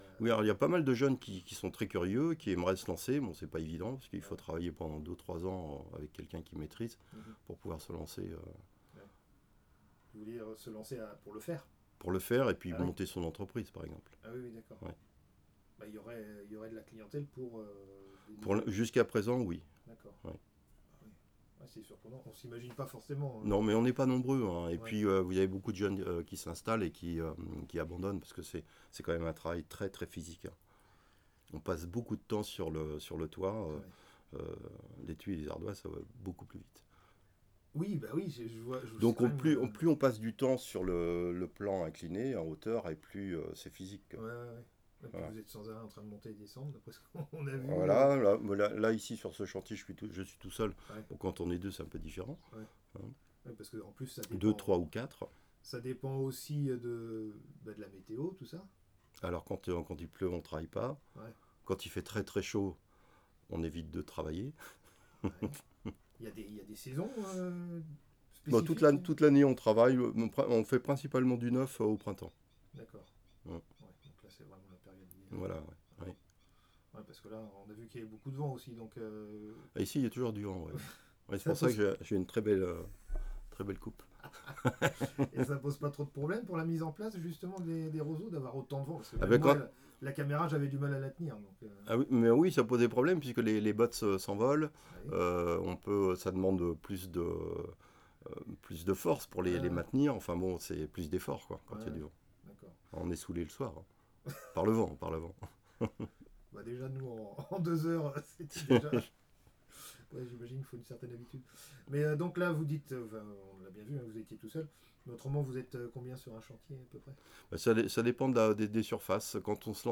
Témoignage sur la couverture végétale
Catégorie Témoignage